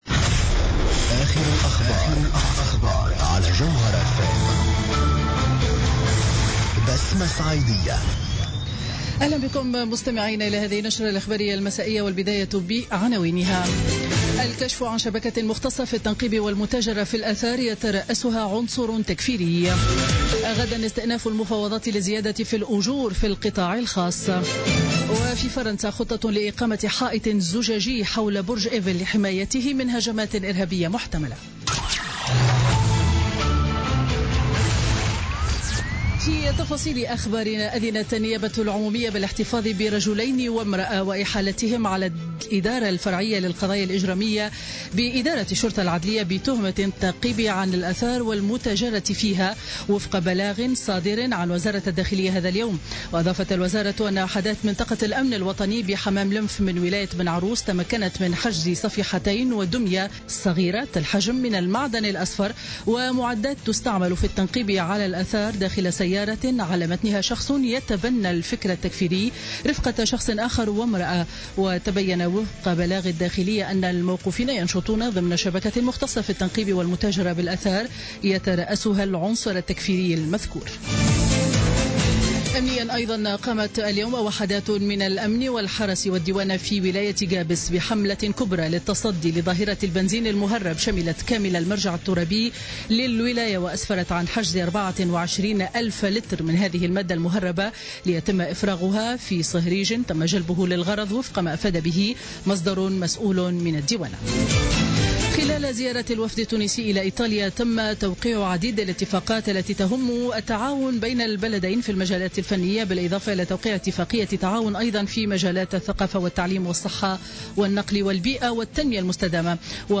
نشرة أخبار السابعة مساء ليوم الخميس 9 فيفري 2017